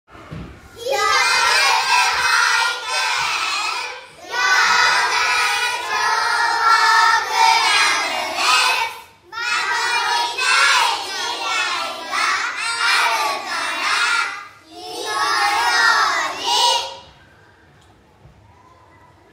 「火災予防運動」や「歳末特別警戒」の一環で、管内の子ども達（幼年消防クラブ員）が録音した音声を消防車から流し、パトロールをします。
子ども達は、かわいい元気いっぱいの声で「守りたい 未来があるから 火の用心」と音声を録音して協力して頂きました。